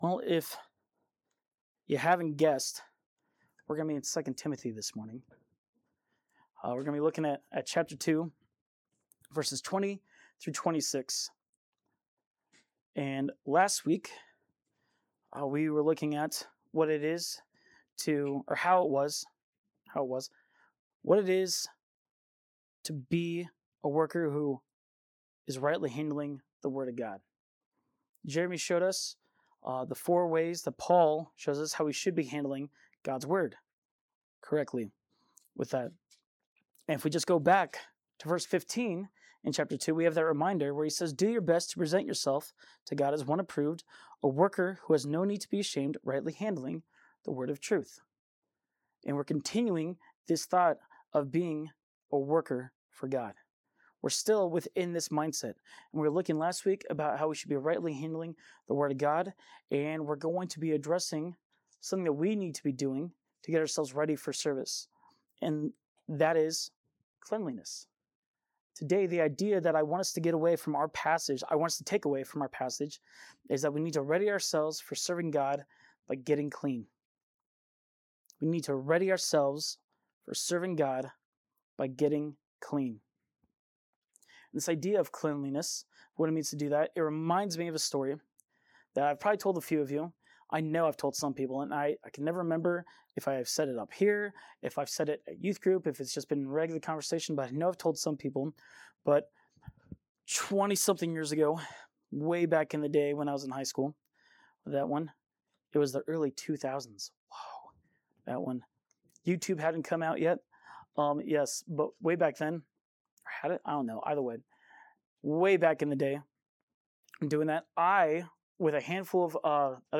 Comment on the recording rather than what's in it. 2 Timothy 2:20-26 • Grace Bible Church, Tremonton, Utah